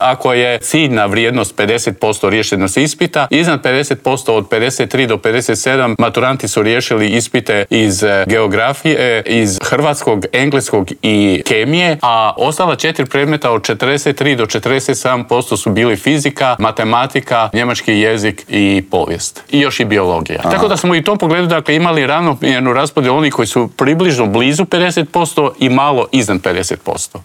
ZAGREB - U Intervjuu tjedna Media servisa razgovarali smo s ravnateljem Nacionalnog centra za vanjsko vrednovanje obrazovanja, Vinkom Filipovićem.